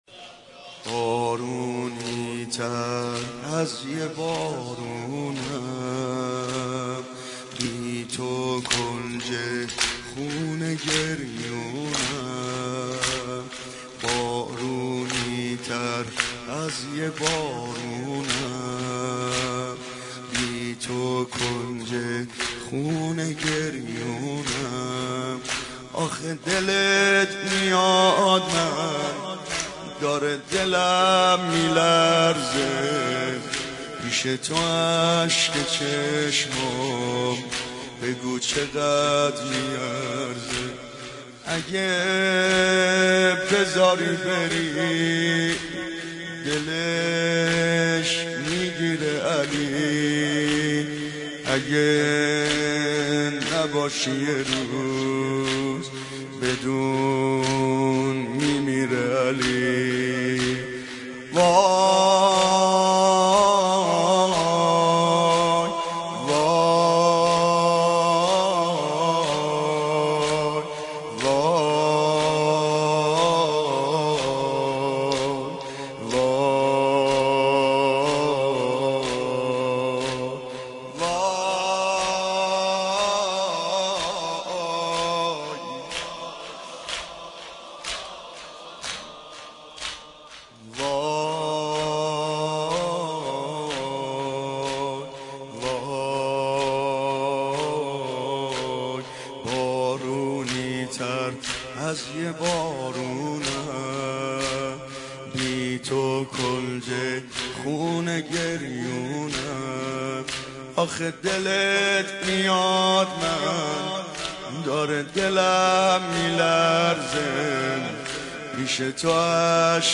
مداحی و نوحه
[سینه زنی زمینه]